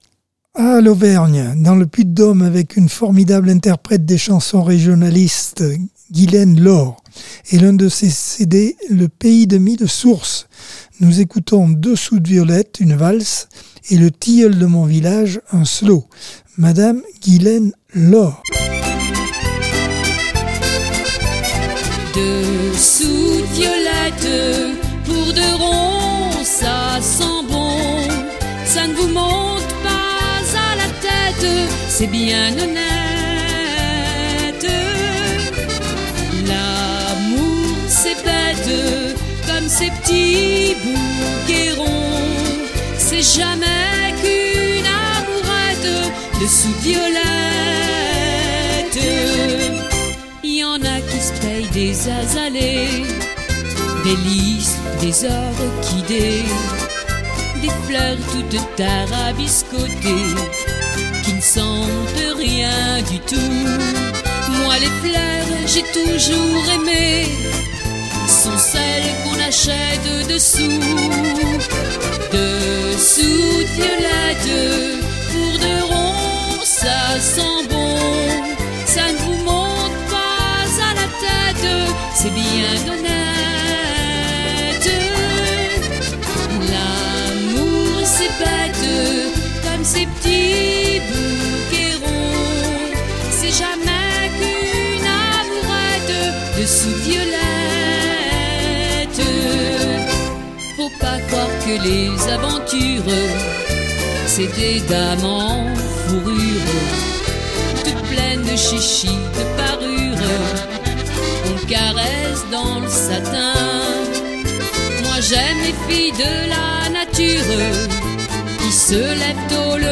Accordeon 2024 sem 28 bloc 4 - Radio ACX